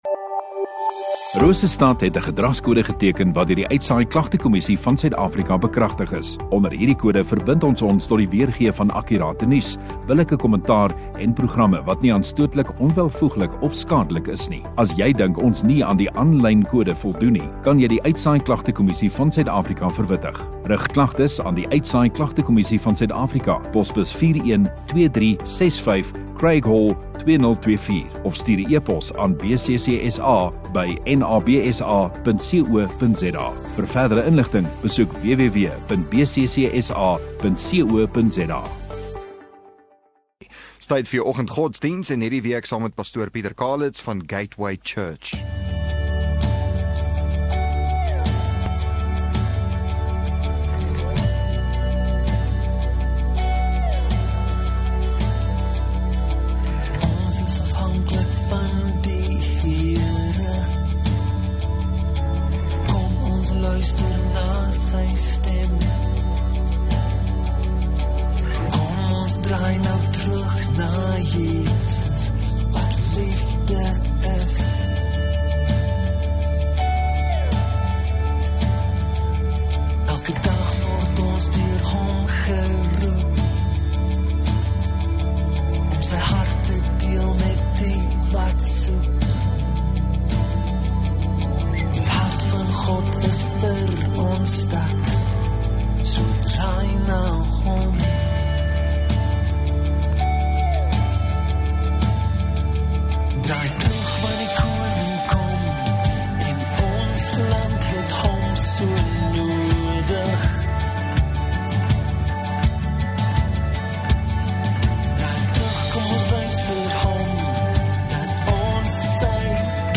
Dinsdag Oggenddiens